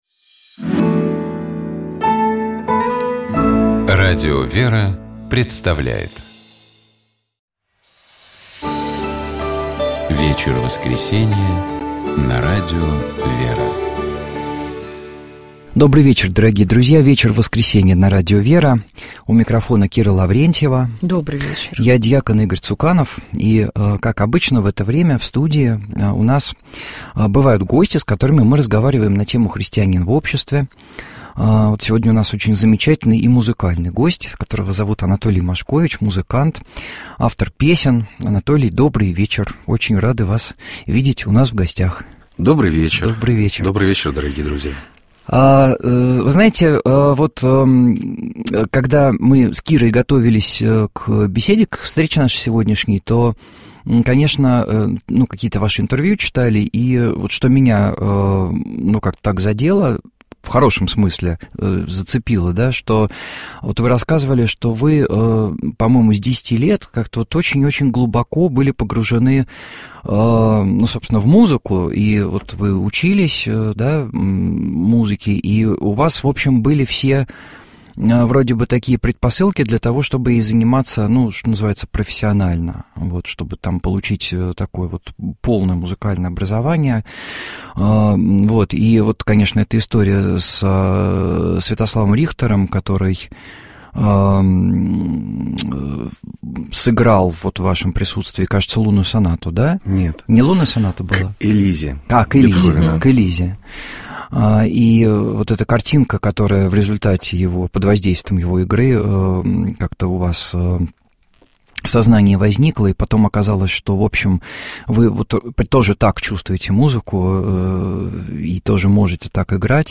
С этими людьми и общаются наши корреспонденты в программе «Места и люди». Отдаленный монастырь или школа в соседнем дворе – мы открываем двери, а наши собеседники делятся с нами опытом своей жизни.